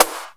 INSNAREFX7-L.wav